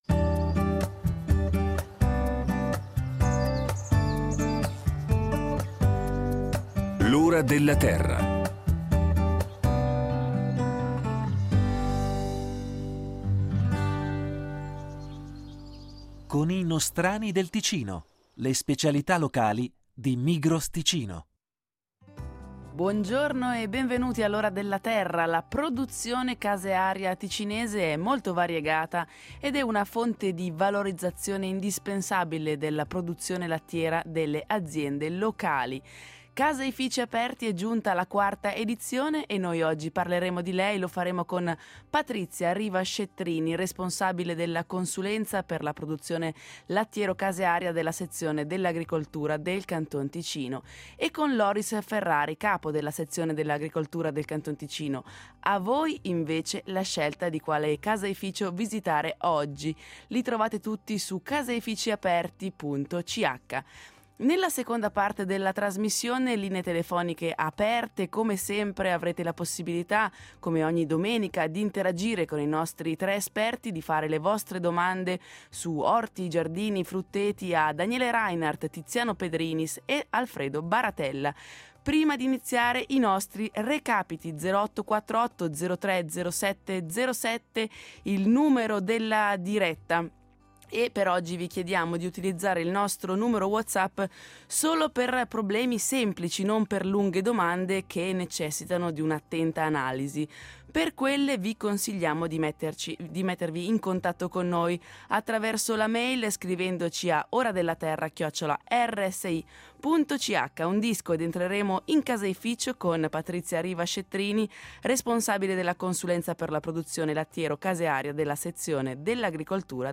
In seguito i tre esperti de L’Ora della Terra rispondono alle domande del pubblico da casa su orti, giardini e frutteti.